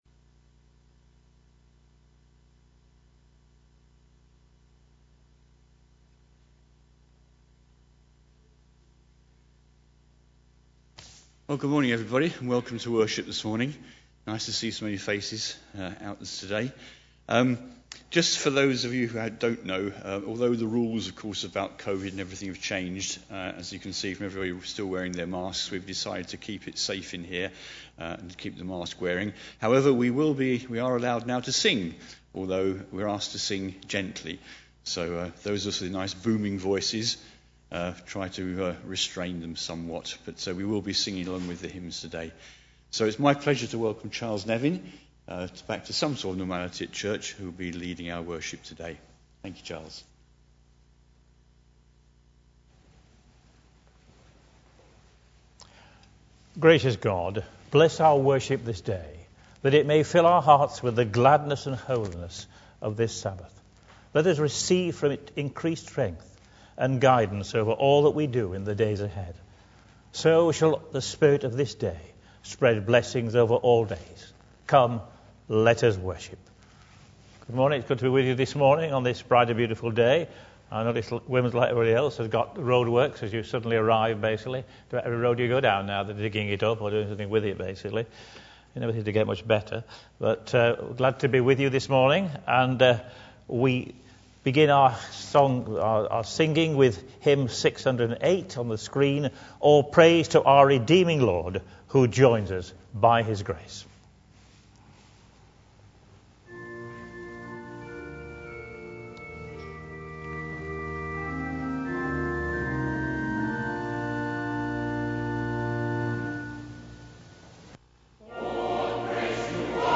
2021-08-01 Morning Worship
Genre: Speech.